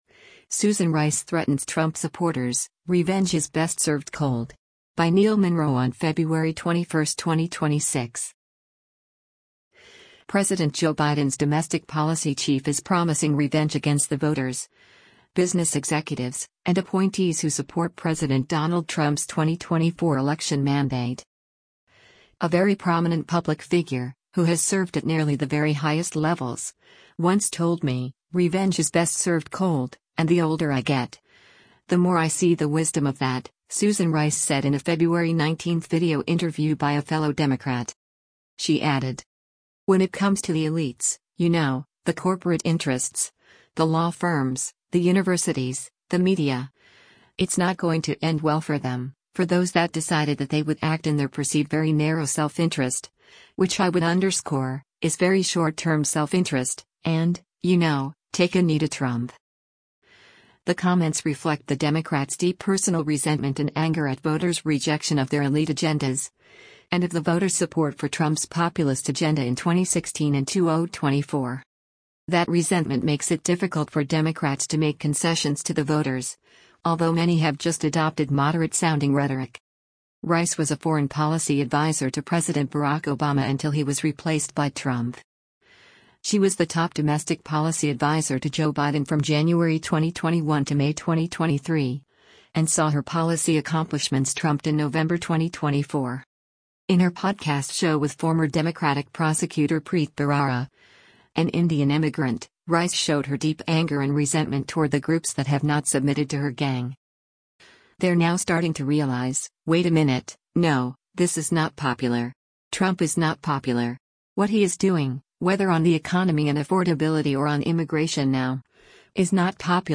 “A very prominent public figure, who has served at nearly the very highest levels, once told me … ‘Revenge is best served cold,’ and the older I get, the more I see the wisdom of that,” Susan Rice said in a February 19 video interview by a fellow Democrat.
In her podcast show with former Democratic prosecutor Preet Bharara, an Indian immigrant, Rice showed her deep anger and resentment toward the groups that have not submitted to her gang: